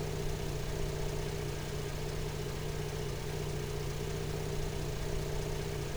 Index of /server/sound/vehicles/vcars/porsche911carrera
idle.wav